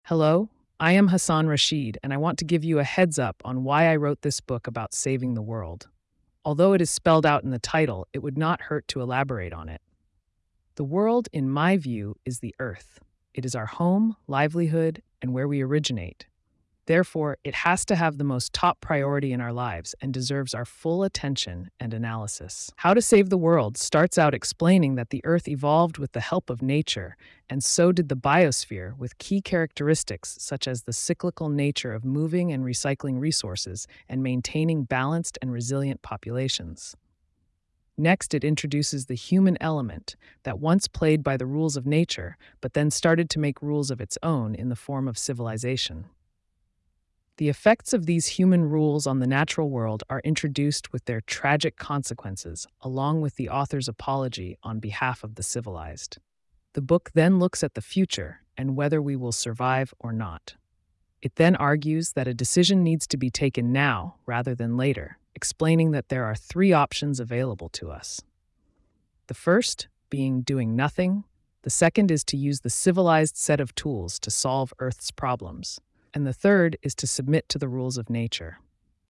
Free .mp3 AudioBook